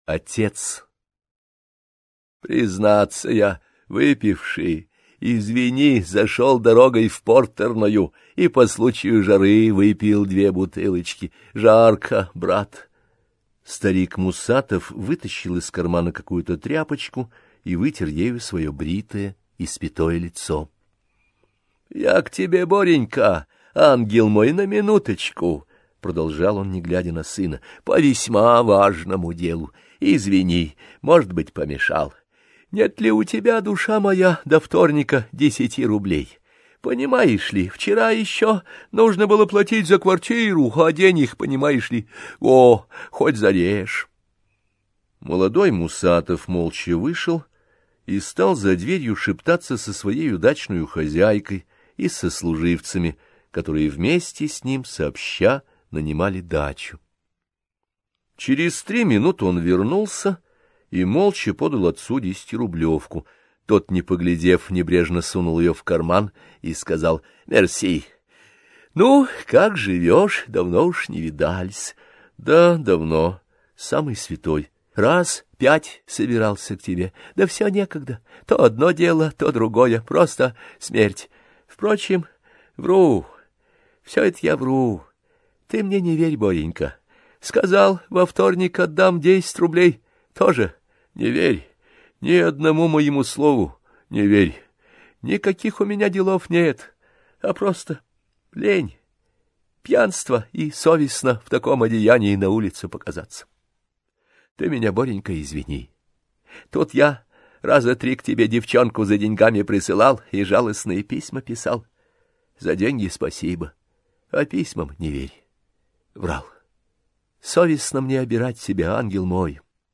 Aудиокнига Черный монах.